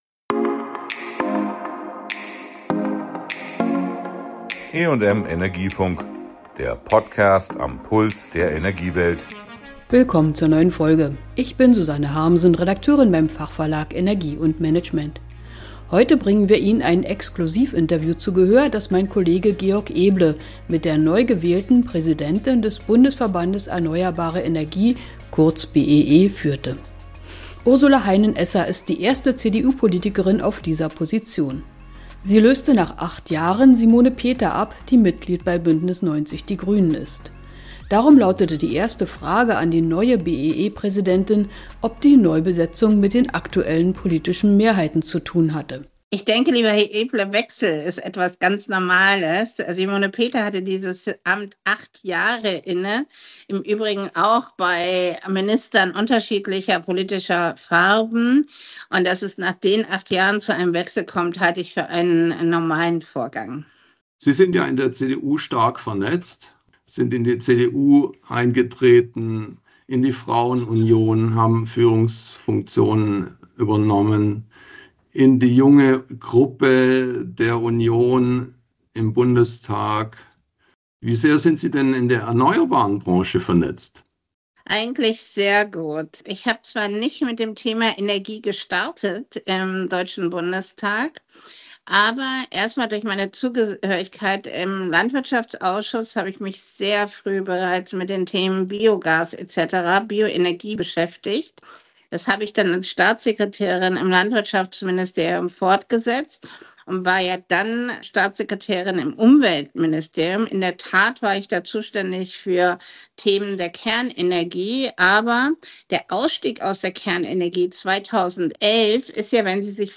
Exklusiv-Interview mit der neuen BEE-Präsidentin Ursula Heinen-Esser über Energiewende, politische Weichenstellungen und flexible Energienutzung.